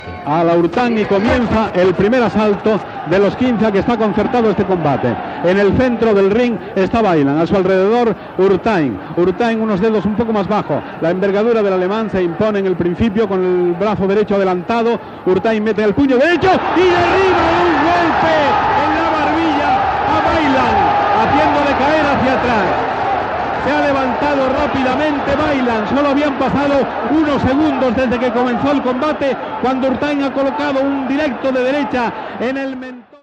Naracció de l'inici del combat de boxa entre José Manuel Urtain i Peter Weiland des del Palacio de los Deportes de Madrid.
Esportiu